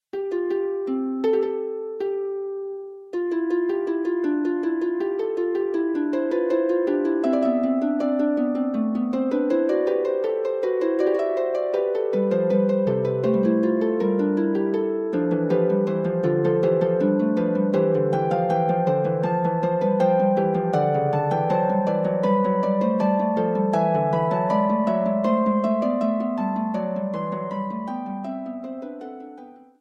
for solo pedal harp.